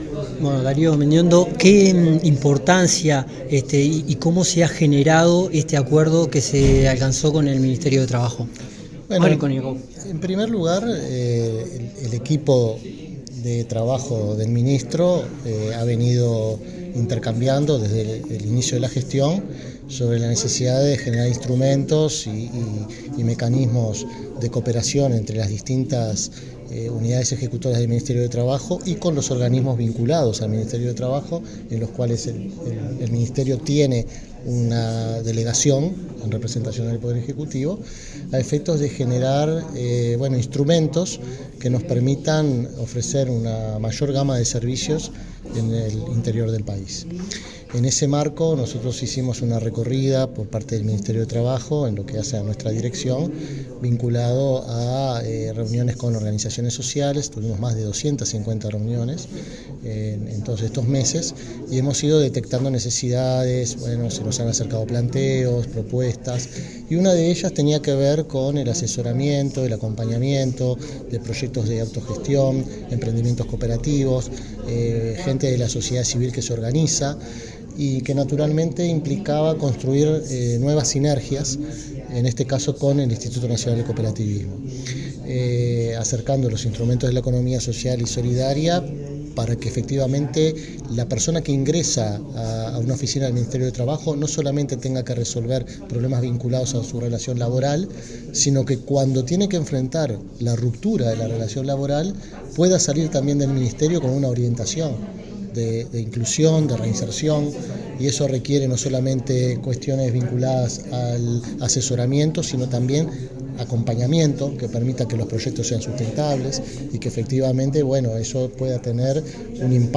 Declaraciones del titular de la Dirección Nacional de Coordinación en el Interior, del Ministerio de Trabajo
En ocasión de la firma de un acuerdo de cooperación entre el Ministerio de Trabajo y Seguridad Social y el Instituto Nacional del Cooperativismo, se